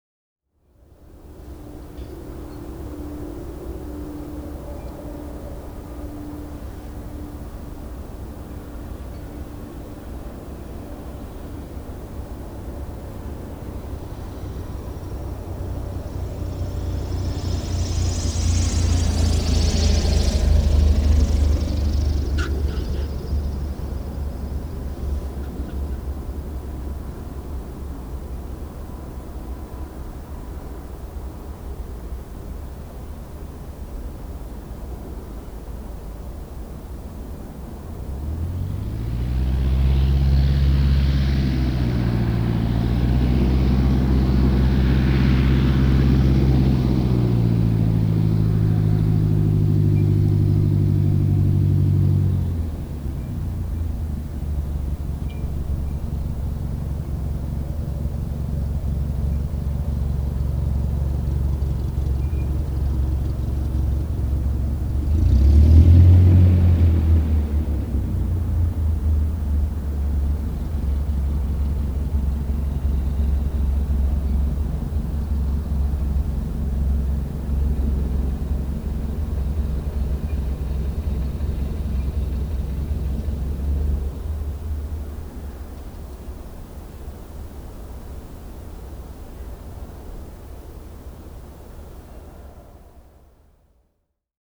landenbadragaz.mp3